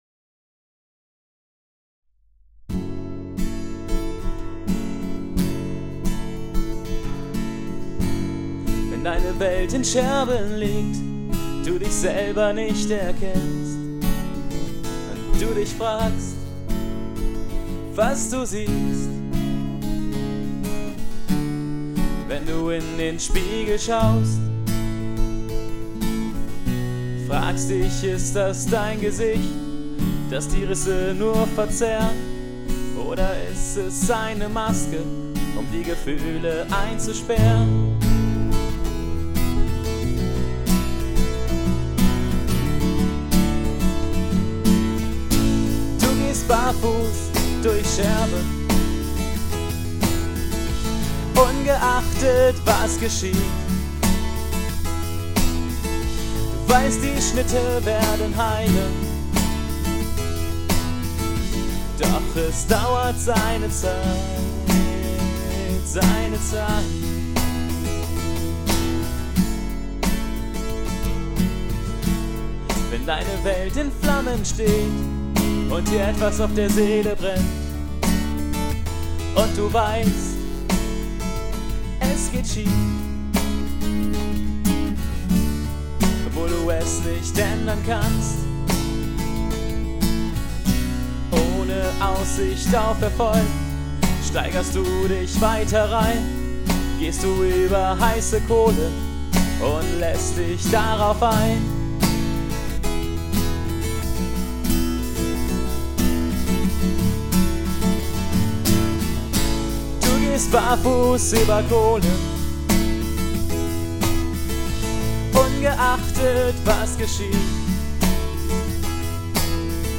Manchmal macht man eine harte Zeit durch, manchmal bringt man sich auch selbst in schwierige Situationen und läuft offenen Auges ins eigene Verderben. Diesen Song habe ich mit mehreren Instrumenten arrangiert und aufgenommen, so dass er sich "poppiger" anhört, als die Aufnahmen nur mit Gitarre und Gesang.